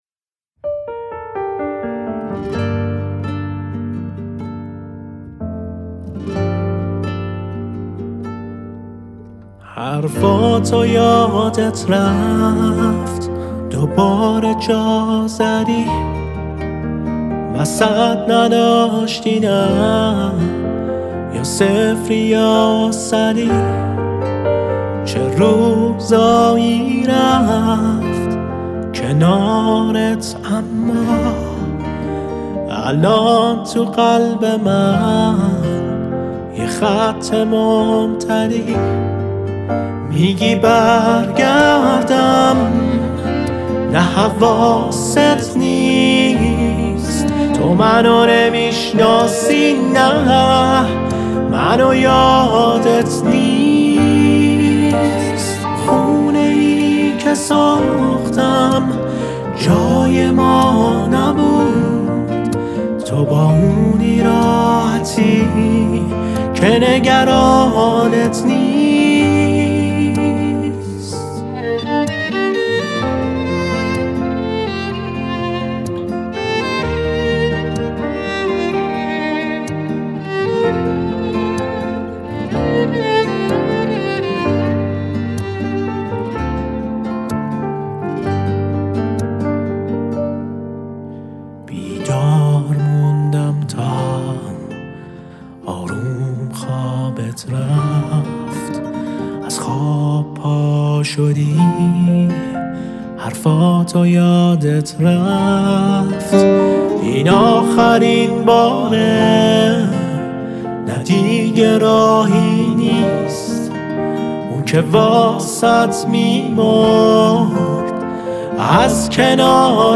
ویولن
پیانو
گیتار
Violin
Piano
Guitar